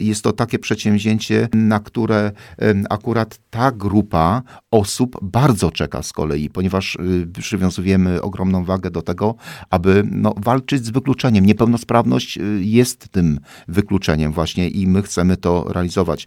Będą oni objęci rehabilitacją i pomocą psychologiczną – mówi wójt gminy Kolno, Józef Wiśniewski.